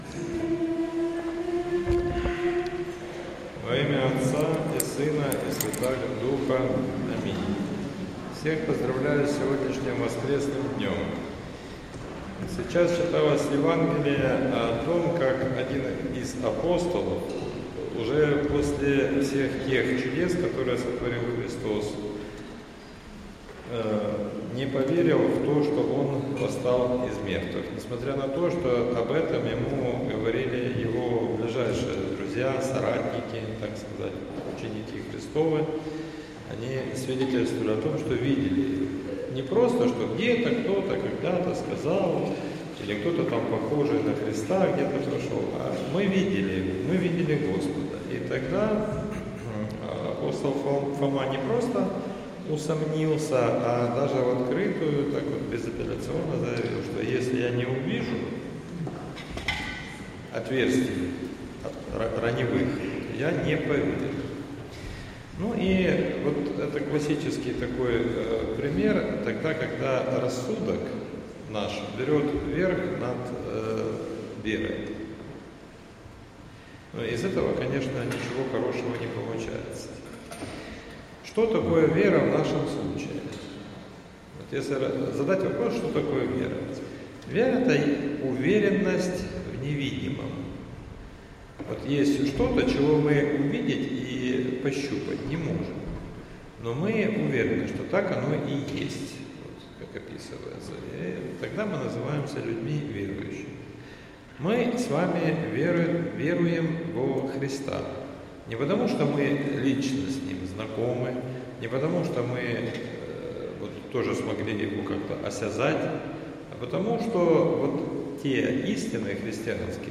Проповедь в девятнадцатую неделю по Пятидесятнице — Спасо-Преображенский мужской монастырь